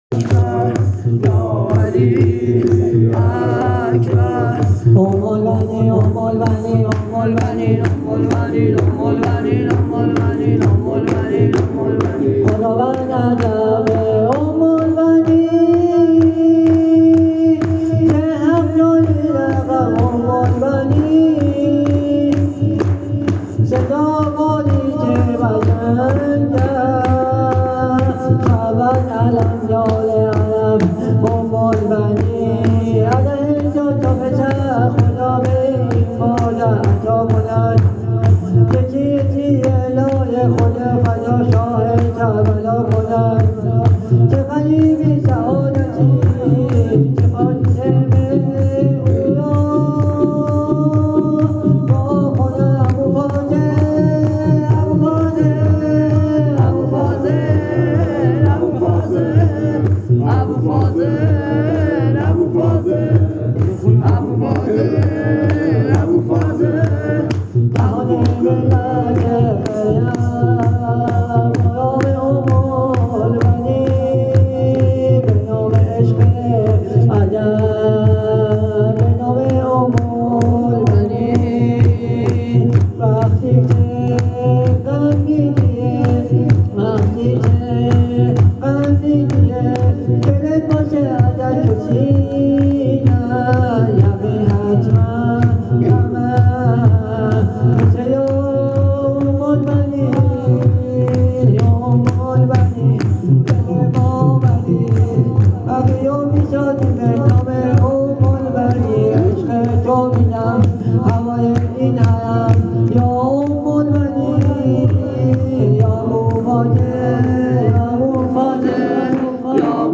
هیت روضه الزهرا تهران